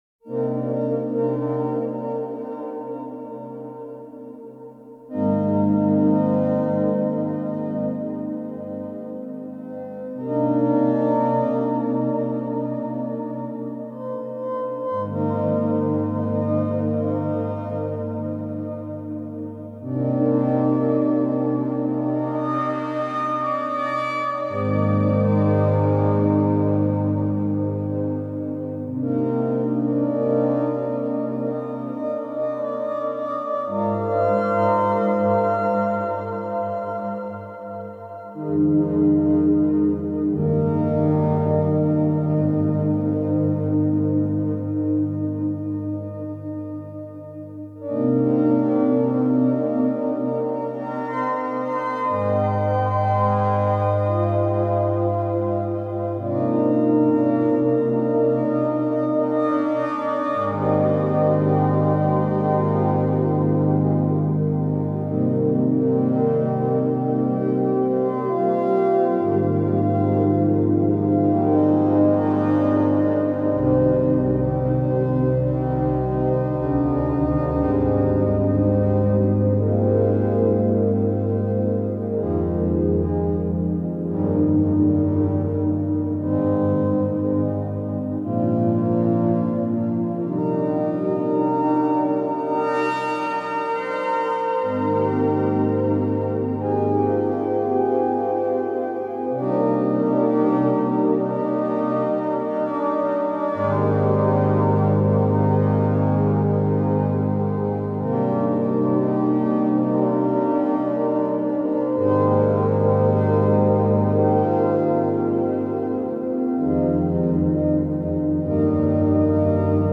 Oberheim Eight Voice
It deserved the finest analog and real-world reverbs.